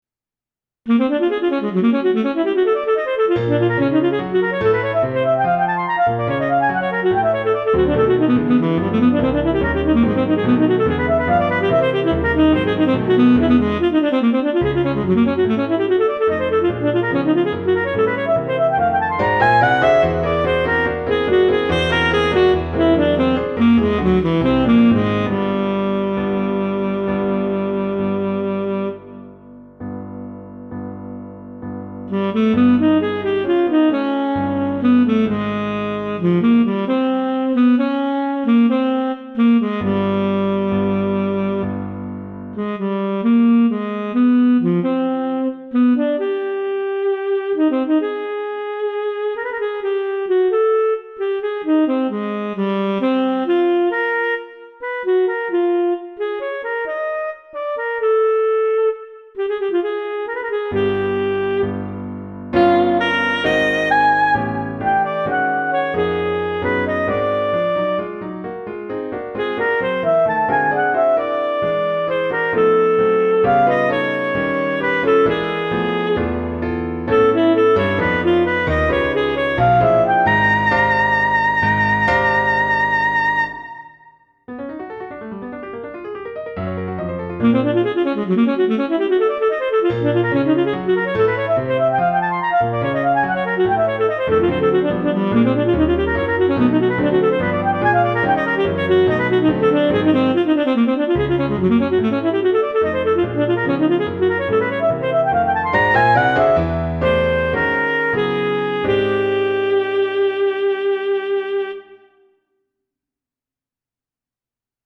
The Piece, composition for alto saxophone and piano.
The Piece – For Alto Saxophone and Piano
This is a short composition, lasting only about two minutes, and is quite simple formally.
There is audio here, made with virtual instruments , so you may roughly hear how it sounds.